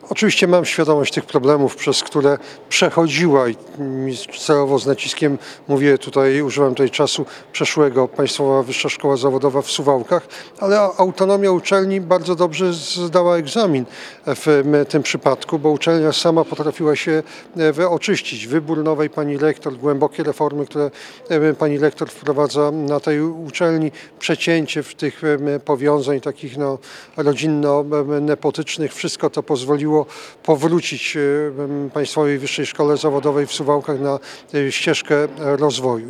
Podczas spotkania z dziennikarzami, pytany o przeszłość suwalskiej uczelni, targanej przed laty różnego rodzaju aferami minister powiedział, że ma świadomość problemów PWSZ.